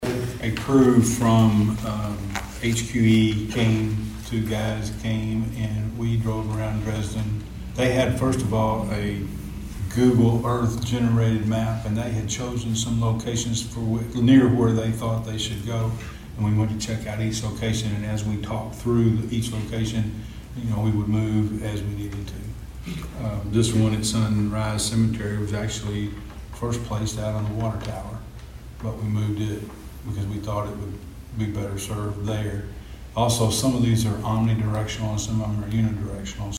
At Dresden’s Planning Commission meeting yesterday, members voted unanimously to install seven new tornado sirens, all funded by The Dresden Rotary Clubs donations.
Mayor Mark Maddox informed the board on how the locations were determined